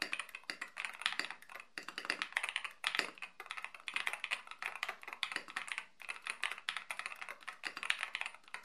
Dodatkowo przełączniki zostały starannie nasmarowane już fabrycznie — ich praca jest cicha i gładka, pozbawiona charakterystycznego dla tanich modeli „szurania” czy nieprzyjemnych zgrzytów. Stabilizatory pod długimi klawiszami również spełniają swoje zadanie — nie chyboczą się, a dźwięk aktywacji, choć wyraźny i donośny, jest przyjemnie głęboki, wręcz satysfakcjonujący.
• Klawiatura jest dosyć głośna.
EPOMAKER-Brick-87-dzwiek.mp3